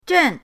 zhen4.mp3